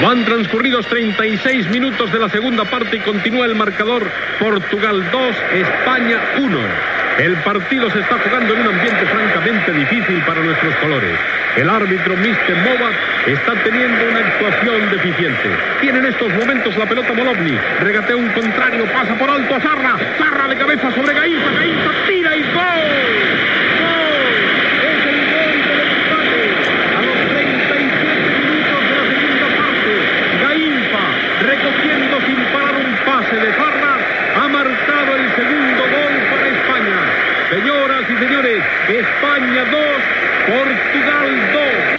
Reconstrucció de la narració del partit de futbol Portugal - Espanya. Gol de Gainza (amb aquest gol la selecció espanyola es va classificar per al mundial de futbol masculí d'Uruguai)
Esportiu
El locutor Matías Prats va fer la reconstrucció d'aquest gol anys després, en no haver cap enregistrament original.
Fragment extret del programa "La radio con botas", emès per Radio 5 l'any 1991.